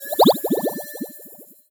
potion_bubble_effect_brew_06.wav